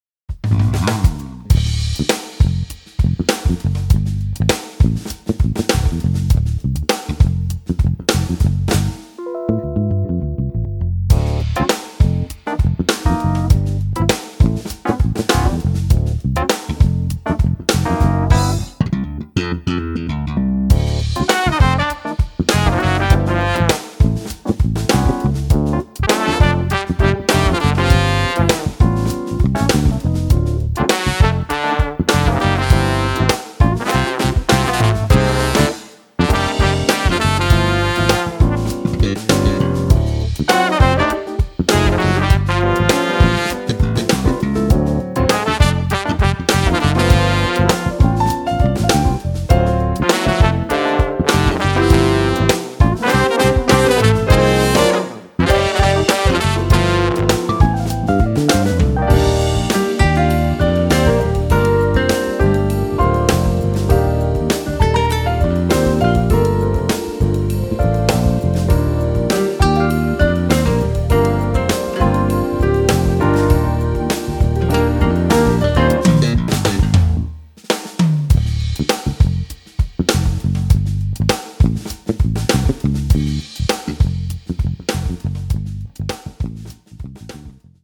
Fat, mix-ready low end